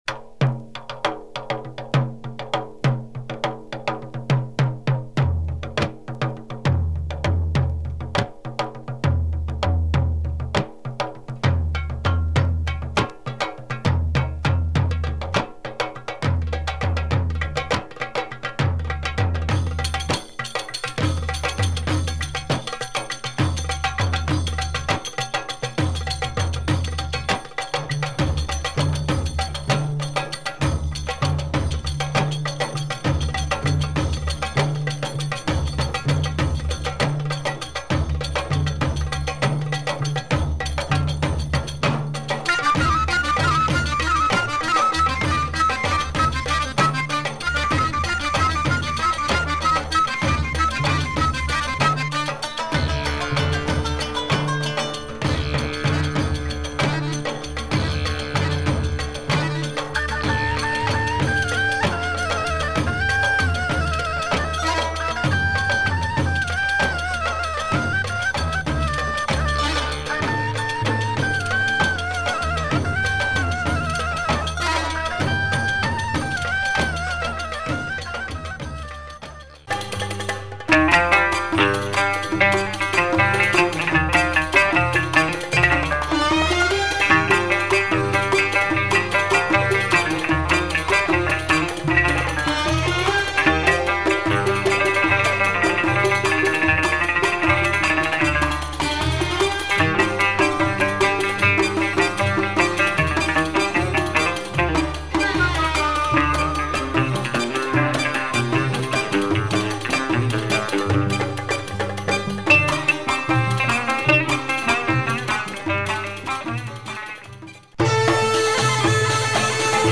Instrumental Lebanese jazz with moog & synthesizers
Crazy sound, quite hard to find now !